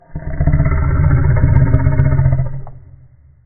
growl-4.ogg